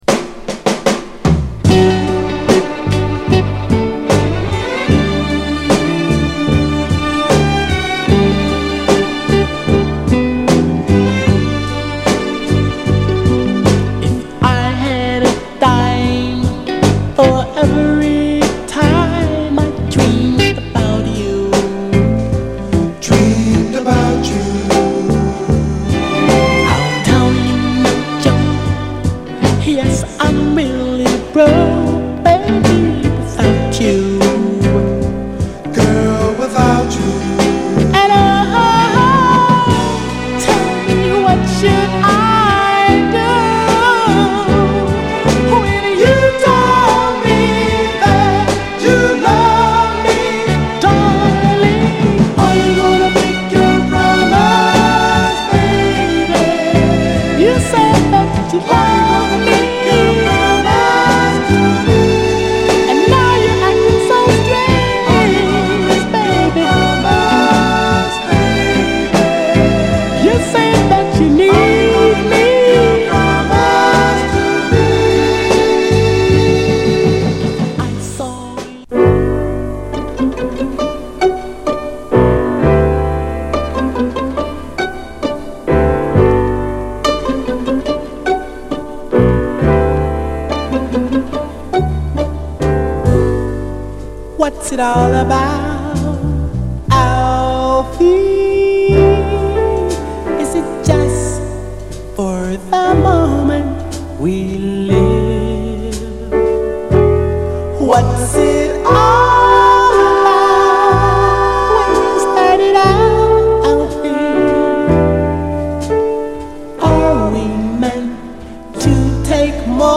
盤はいくつか細いスレ箇所ありますが、グロスがありプレイ良好です。
※試聴音源は実際にお送りする商品から録音したものです※